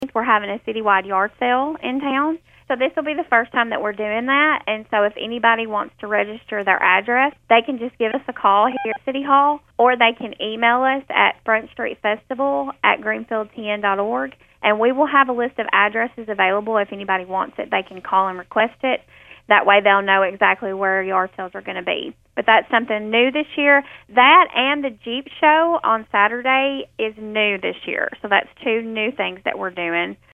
City Recorder Jerica Spikes tells Thunderbolt more about this city wide event and another new event they’ll be having Saturday.